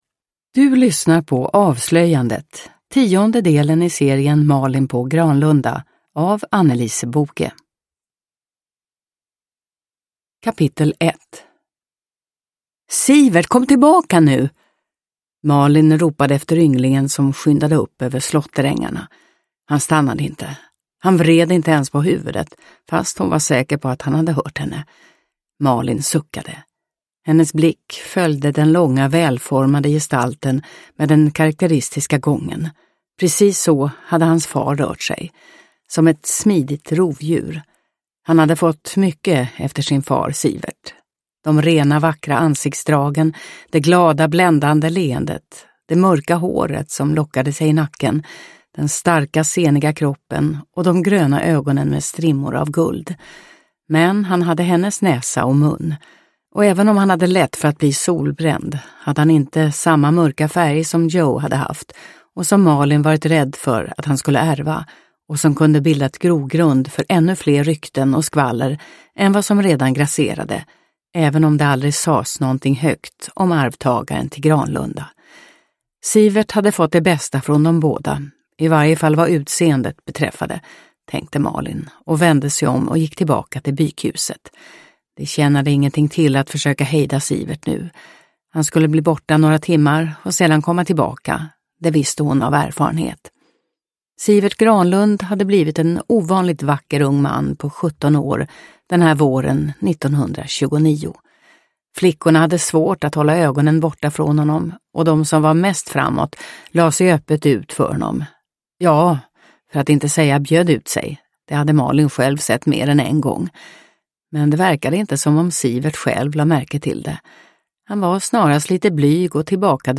Avslöjandet – Ljudbok – Laddas ner